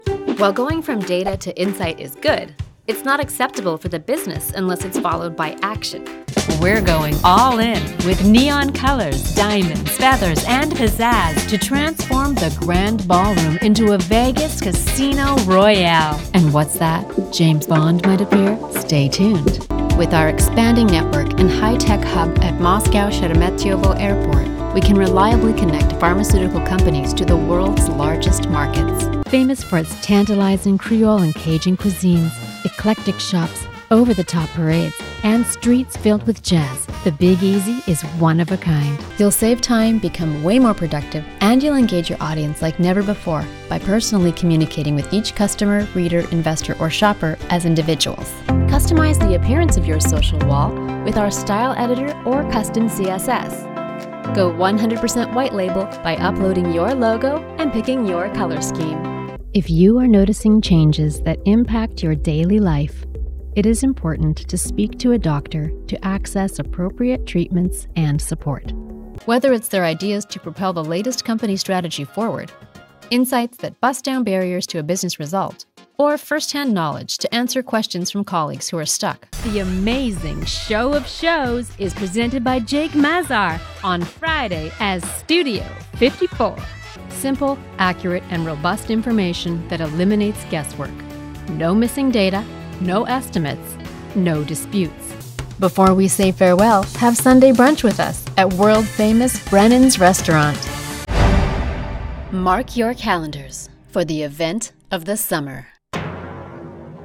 My range is from warm, genuine and trustworthy to high energy and engaging.